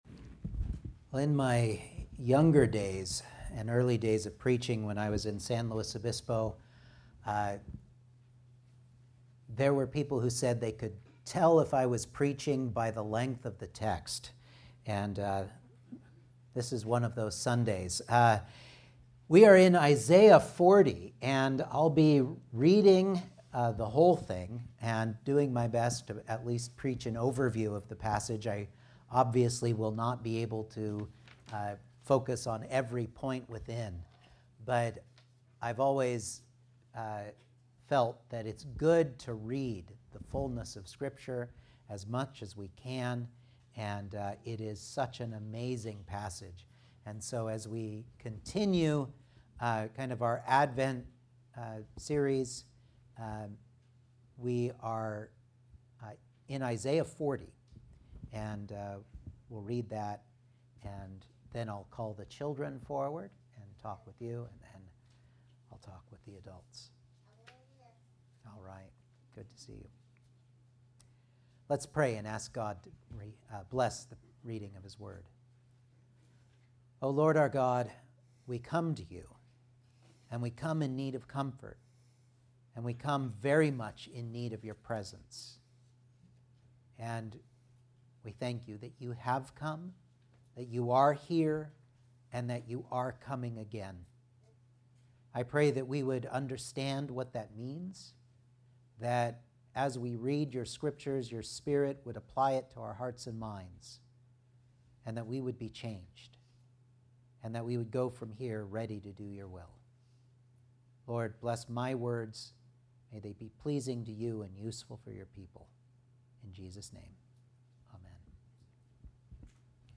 Isaiah 40 Service Type: Sunday Morning Outline